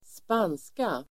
Uttal: [²sp'an:ska]